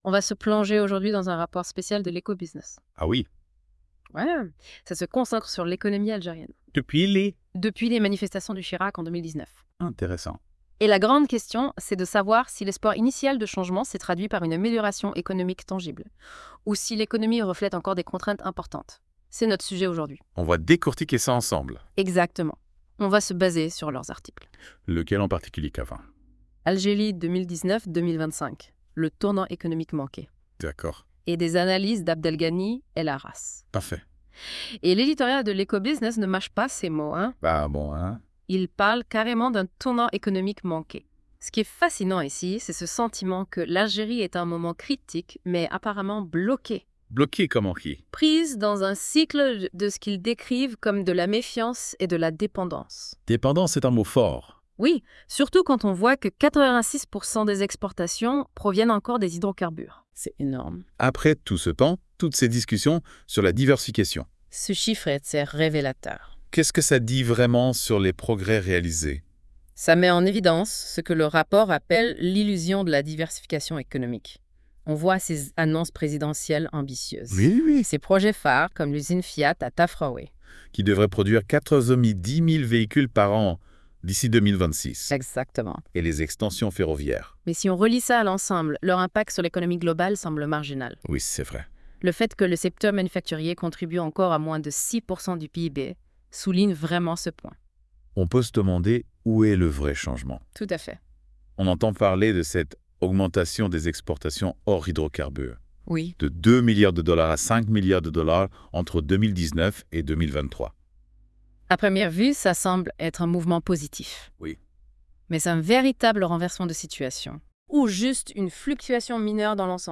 Débat (36.06 Mo) 1. Quel bilan peut-on tirer de l'économie algérienne six ans après le Hirak de 2019 ? 2. Pourquoi la diversification économique reste-t-elle un défi majeur pour l'Algérie ? 3.